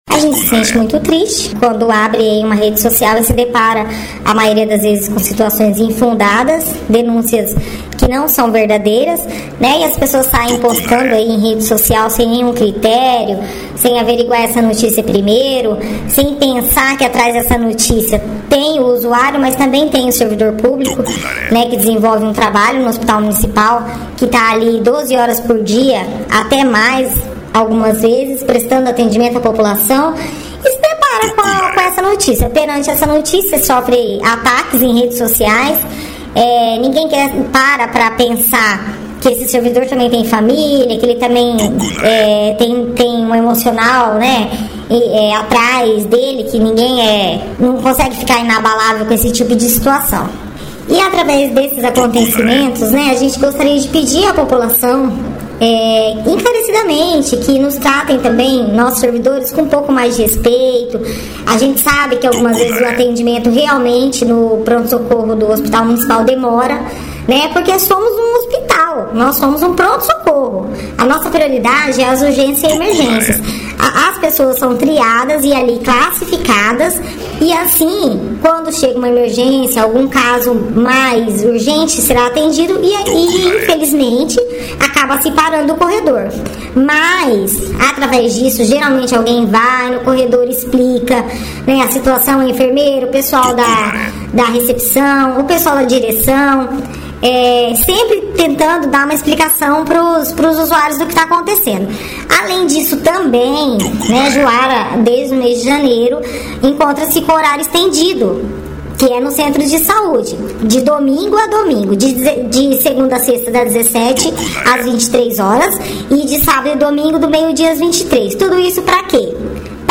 Os áudios com os relatos foram gravados com autorização das servidoras e tiveram suas vozes modificadas para garantir o anonimato, no entanto, o conteúdo completo com a denúncias em áudio modificado estão postadas no final dessa matéria e o conteúdo original, sem alterações, bem como os nomes e identificação ficarão à disposição da justiça, caso seja solicitado.
AUDIOS DAS ENTREVISTAS (COM AS VOZES MODIFICADAS) REALIZADAS NA TARDE DO DIA 28.05.2025 NO HOSP MUNICIPAL DE JUARA NA PRESENÇA DA DIREÇÃO: